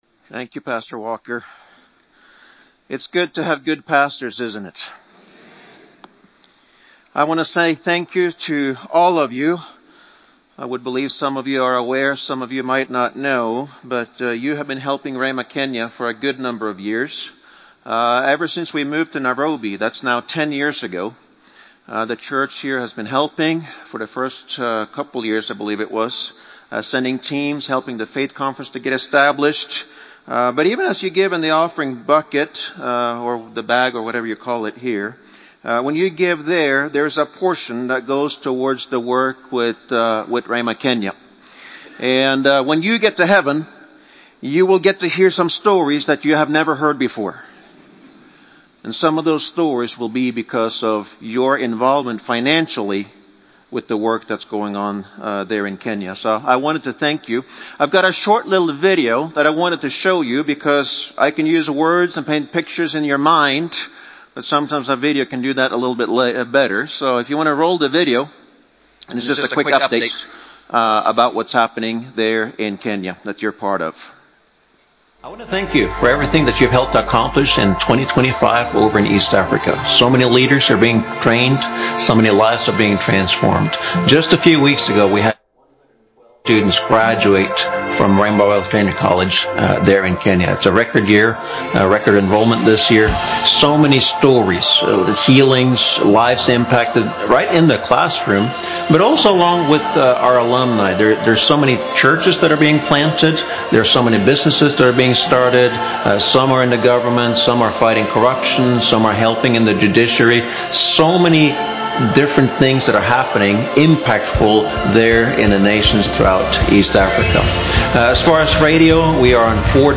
From Series: "Individual Sermons"